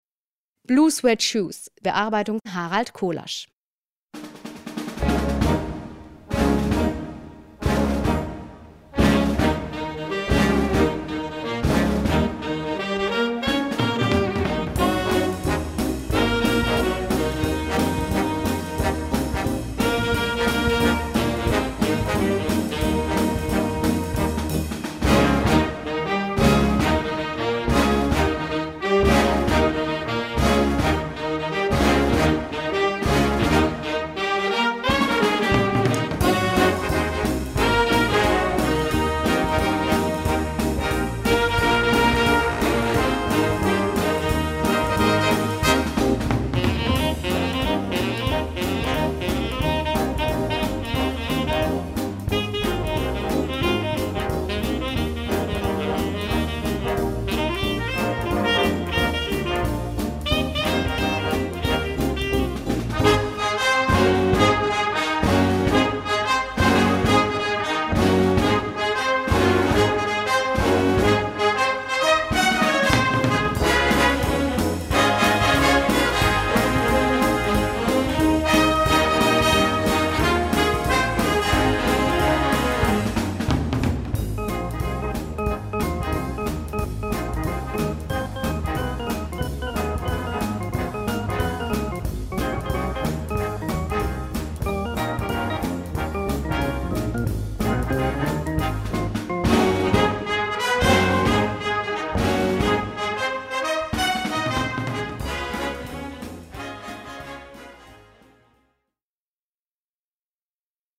Gattung: Rock
Besetzung: Blasorchester